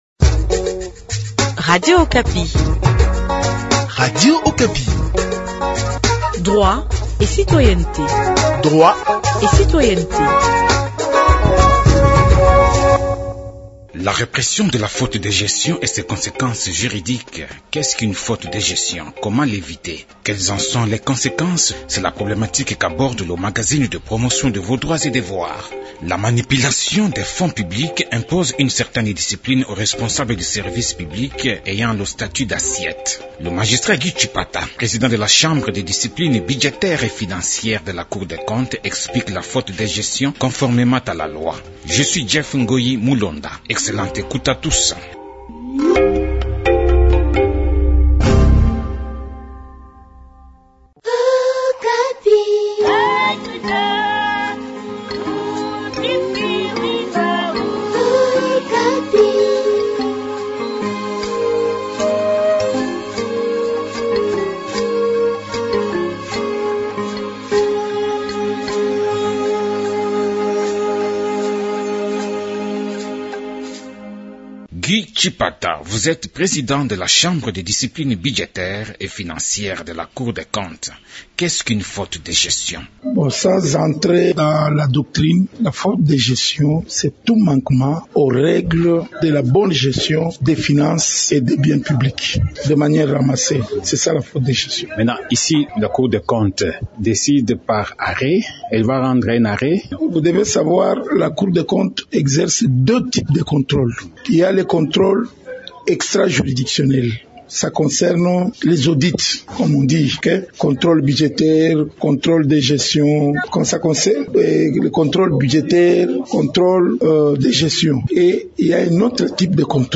Le magistrat Guy Tshipata, président de la Chambre de discipline budgétaire et financière de la Cour des comptes explique la faute de gestion conformément à la loi. Et le premier président de cette haute institution financière du pays, Jimmy Munganga dévoile le contenu du rapport d’audit sur les recettes judiciaires.